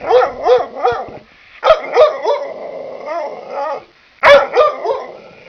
cane.wav